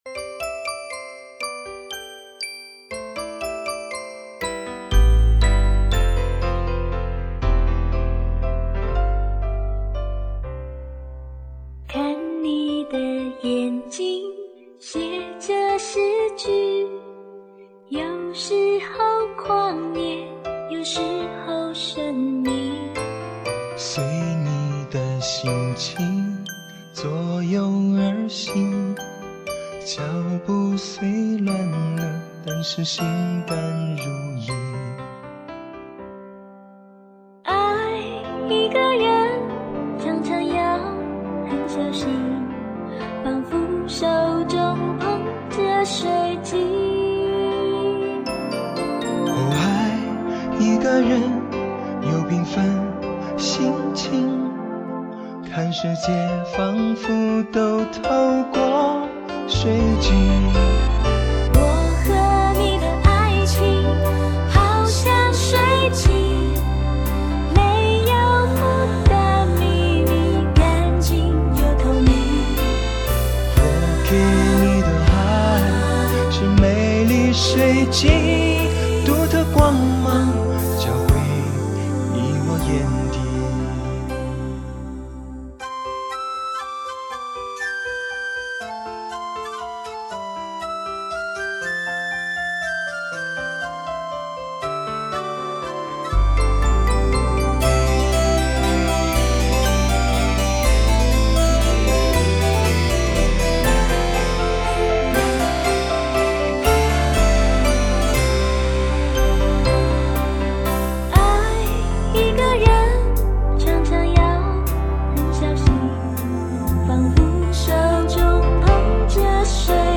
（来个对唱的 ）